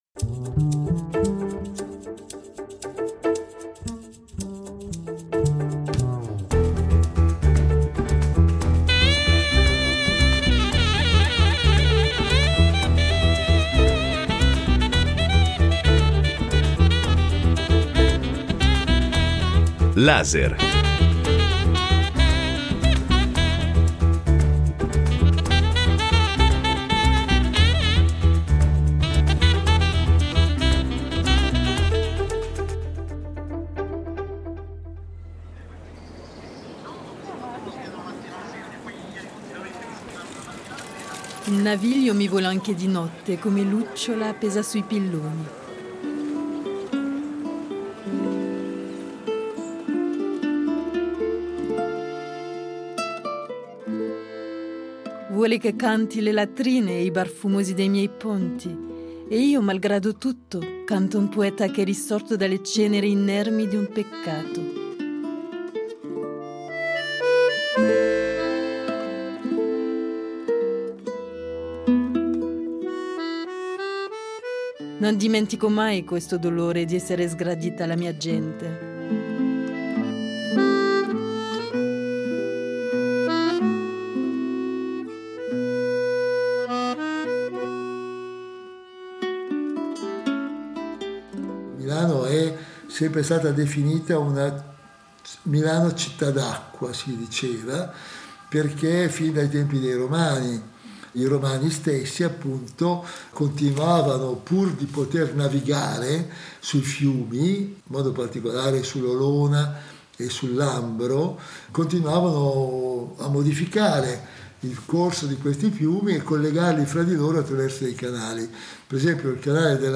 Un viaggio sonoro storico e utopico sui canali che collegano la città di Milano al Ticino e all’Adda. Una navigazione radiofonica che si propone di immaginare una città di nuovo attraversata dai suoi canali, oggi interrati